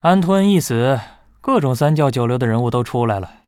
文件 文件历史 文件用途 全域文件用途 Bhan_tk_04.ogg （Ogg Vorbis声音文件，长度3.5秒，106 kbps，文件大小：45 KB） 源地址:地下城与勇士游戏语音 文件历史 点击某个日期/时间查看对应时刻的文件。